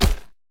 creaking_sway4.ogg